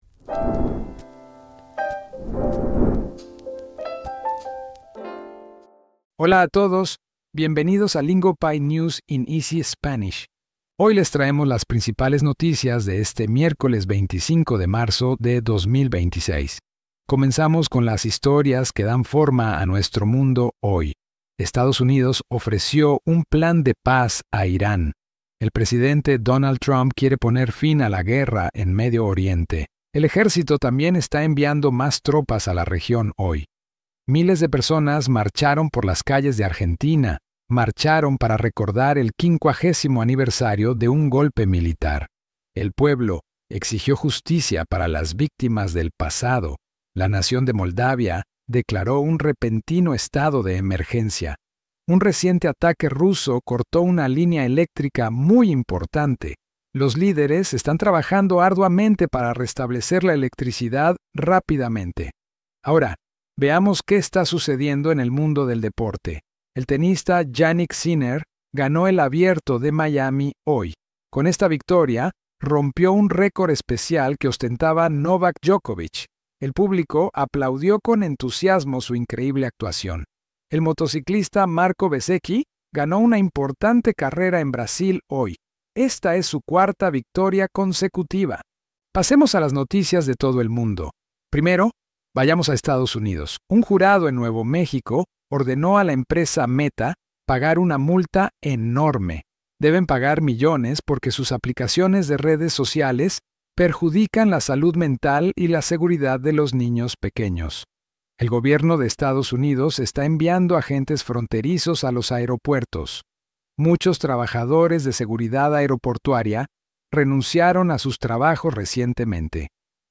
Big headlines, slowed down. This episode delivers the main stories in clear, beginner-friendly Spanish, so your listening practice stays simple.